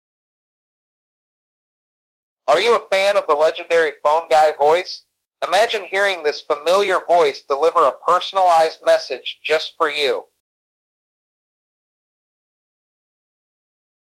Custom Voice Message with Phone Guy Voice
The Phone Guy voice has captivated audiences with its distinctive tone and nostalgic appeal.
• Authenticity: Our voice artist delivers an impeccable Phone Guy impression, ensuring your message sounds as authentic as possible.
• High-Quality Audio: Enjoy a polished, professional recording that’s clear, crisp, and ready for any occasion.